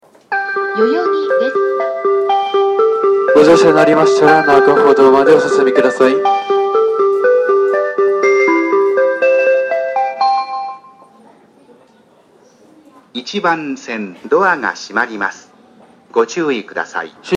発車メロディーフルコーラスです。